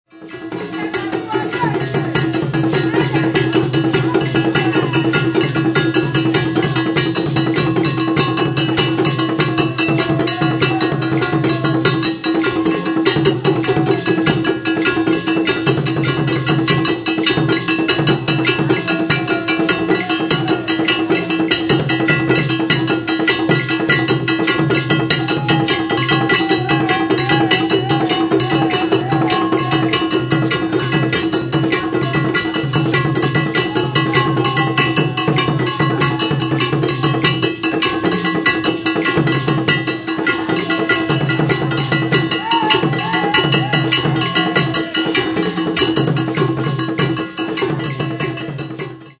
The brenko is an open single-headed goblet drum that is part of the adowa ensemble.
The membrane is stretched across the head of the drum inside a metal ring, which is anchored with braided ropes to five conical wooden pegs.
The brenko drum is played with the palm of the hand and the fingertips alternately.